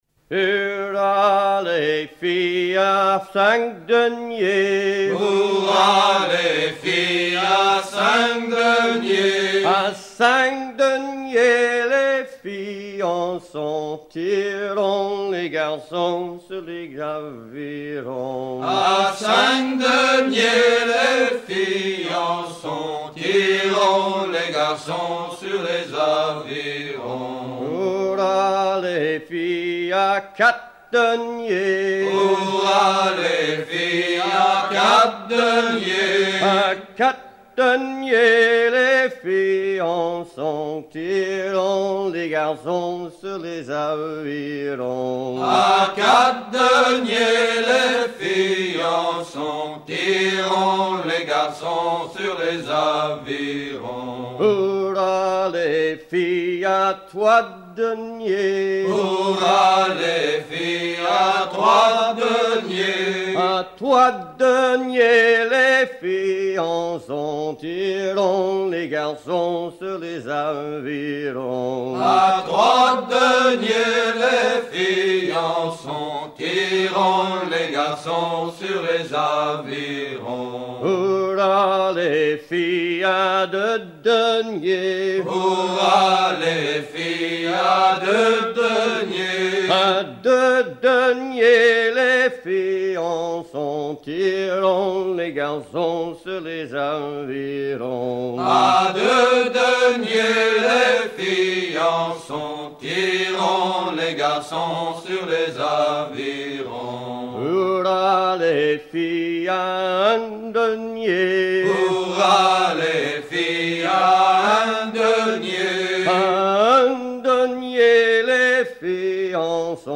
Fonction d'après l'analyste gestuel : à ramer ;
Genre énumérative